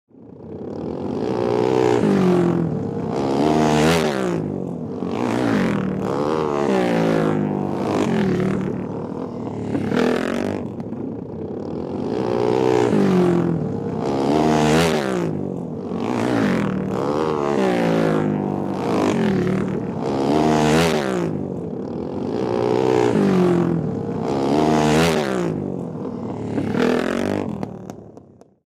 Звуки мотокросса